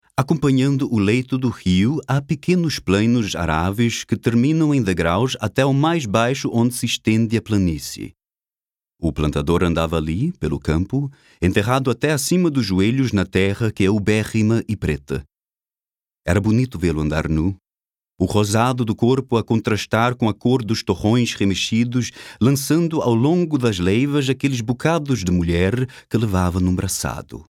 Sprechprobe: Sonstiges (Muttersprache):
voice over artist: german, brasilian, portuguese.